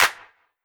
• Hand Clap Sound C Key 19.wav
Royality free clap one shot - kick tuned to the C note. Loudest frequency: 3307Hz
hand-clap-sound-c-key-19-Ndx.wav